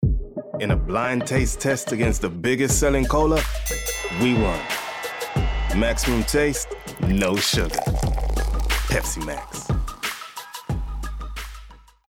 20/30's London, Natural/Warm/Relaxed
Pepsi (US accent)